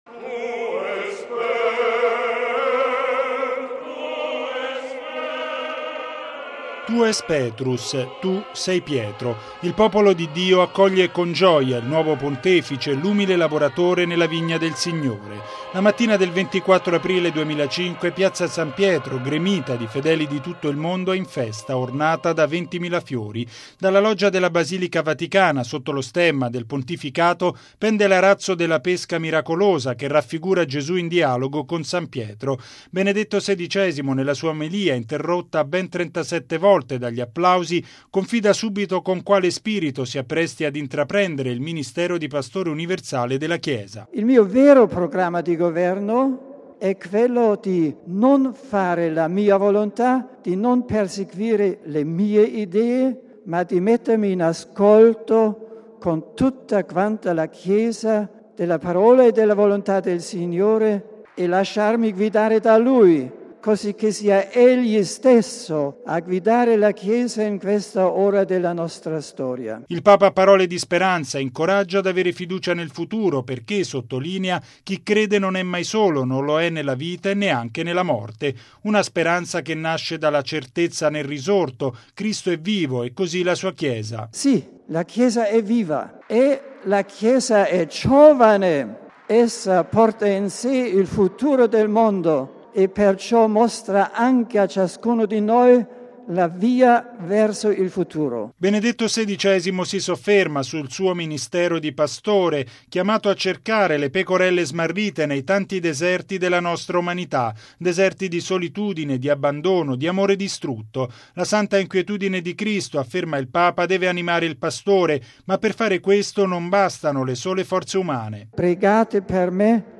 Benedetto XVI, nella sua omelia interrotta ben 37 volte dagli applausi, confida subito con quale spirito si appresti ad intraprendere il ministero di Pastore universale della Chiesa: